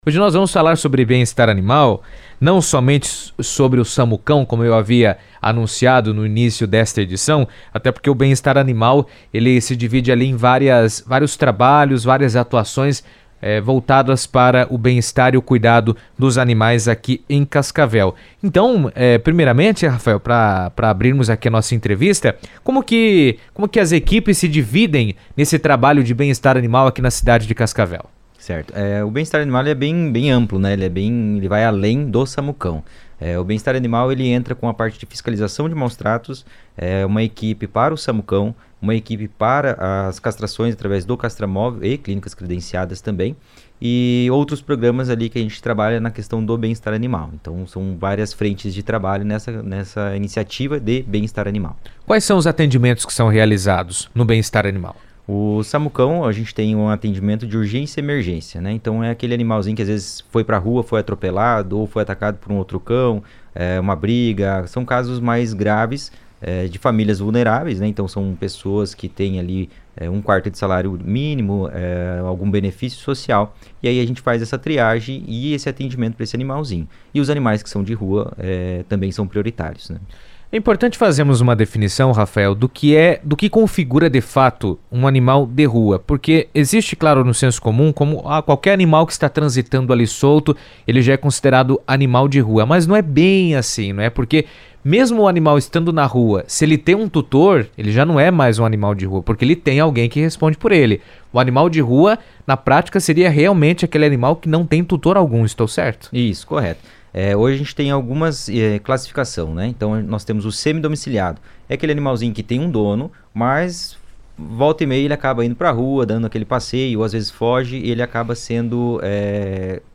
Em entrevista à CBN Cascavel